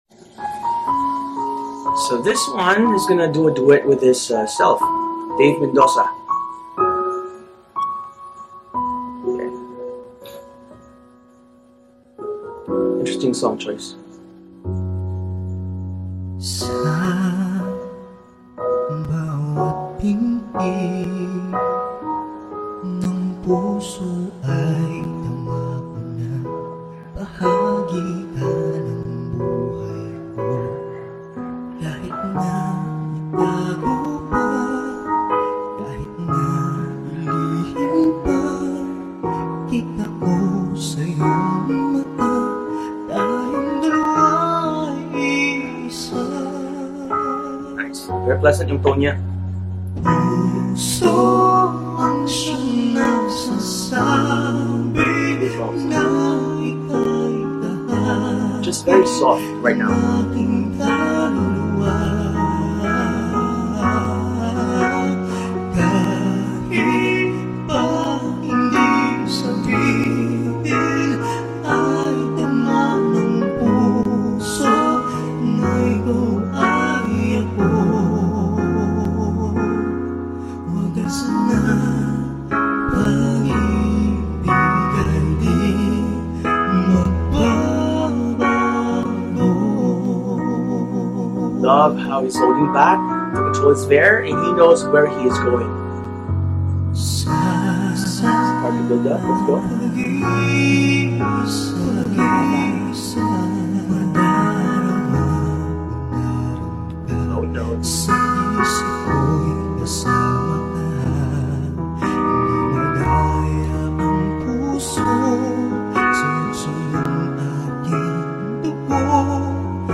Guy duets with himself to sing a male version
Such a clean male cover ought to be applauded.